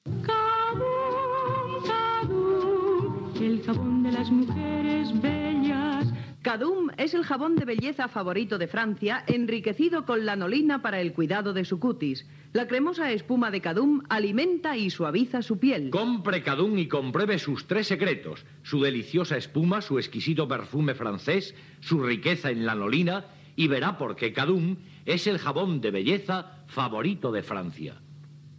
Fragment extret del programa "Ràdio Girona, 65 anys" emès per Ràdio Girona 2 l'any 1998